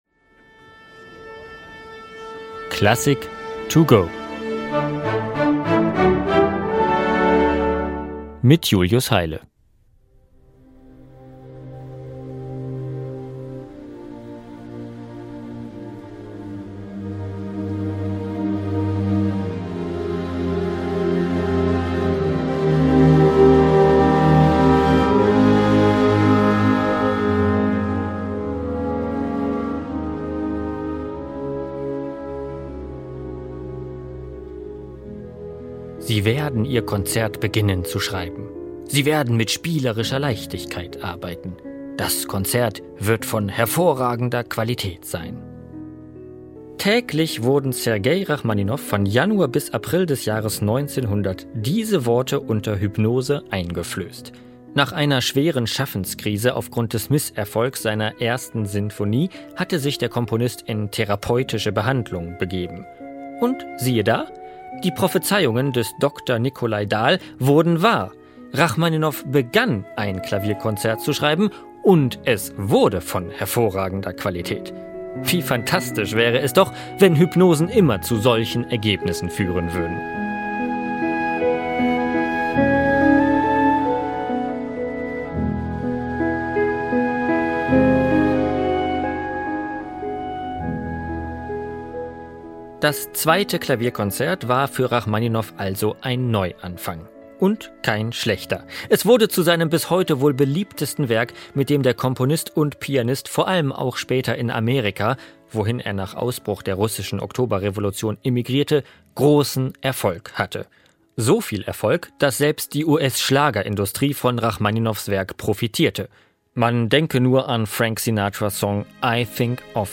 Werkeinführung für unterwegs